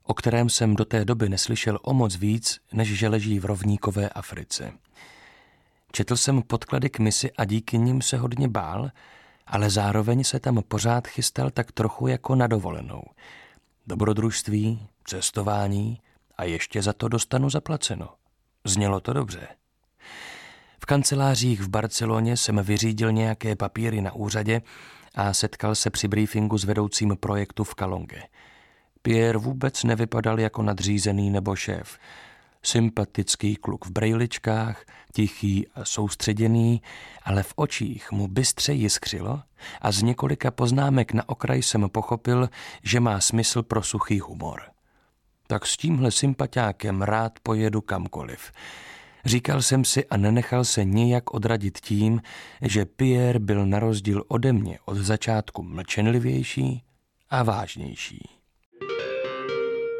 Audiobook
Audiobooks » Short Stories